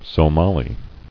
[So·ma·li]